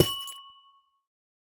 Minecraft Version Minecraft Version latest Latest Release | Latest Snapshot latest / assets / minecraft / sounds / block / amethyst / break3.ogg Compare With Compare With Latest Release | Latest Snapshot
break3.ogg